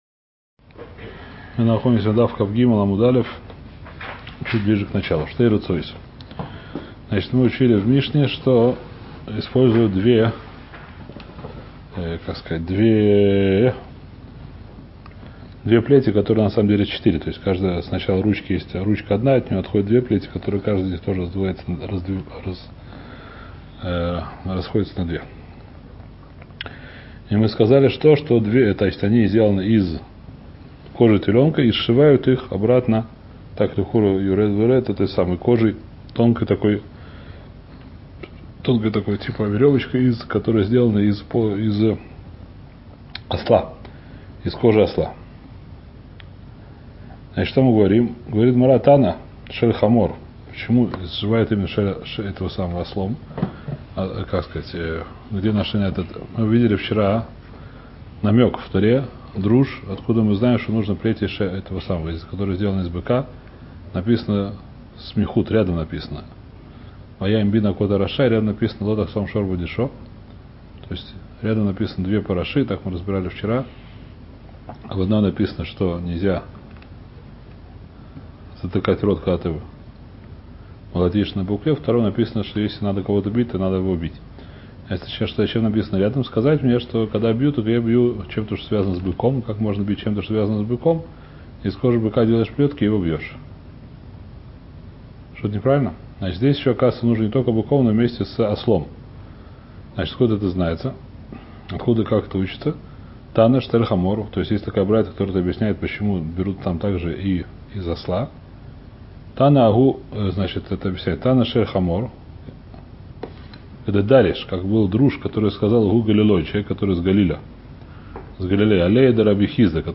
Трактат Макот — Урок 161 — Лист 23а